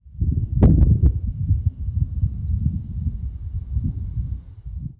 454風,登山,山頂,暴風,強風,自然,
効果音自然野外